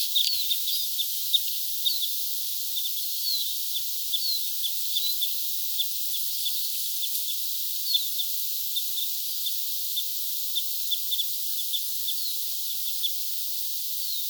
käpylintuja lentää
kapylintuja_lentaa.mp3